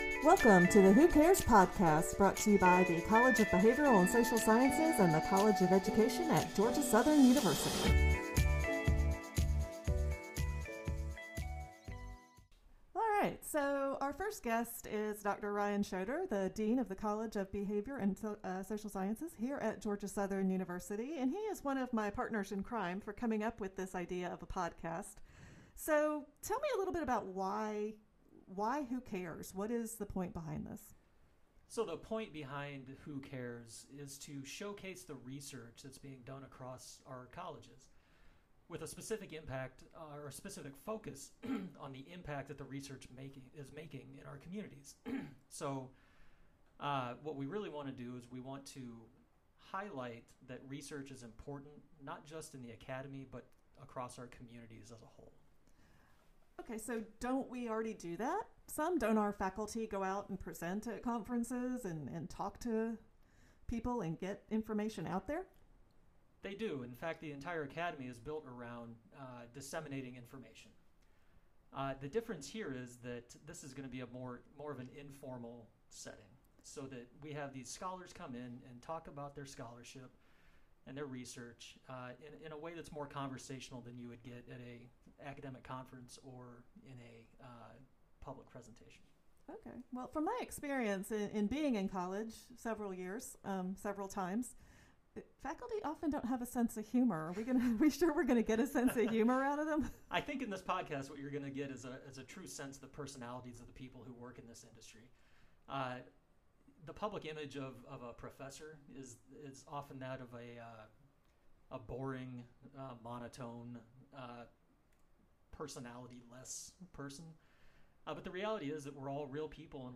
Research is taking place across the University on a daily basis, and it's important to talk about and highlight the kinds of research that takes place. So let's make this fun, interesting, casual, conversational and give our audience a true sense of the personalities of our scholars.